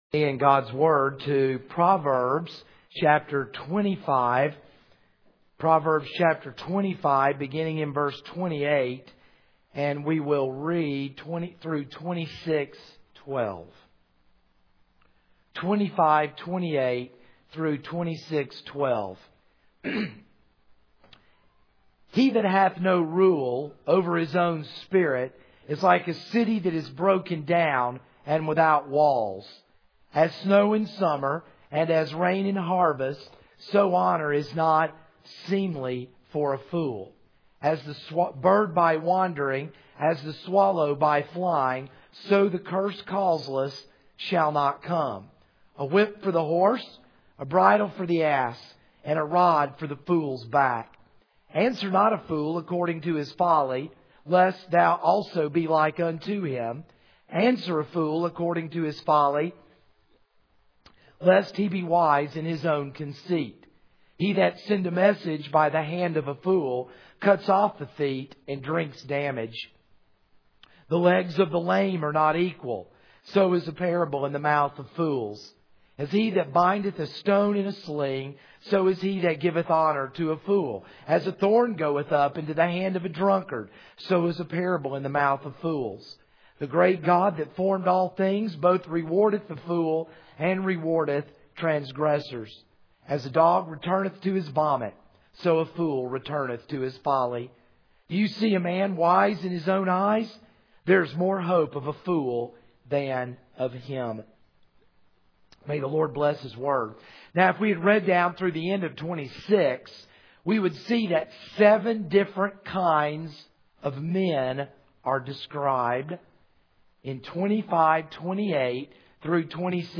This is a sermon on Proverbs 25:28-26:28.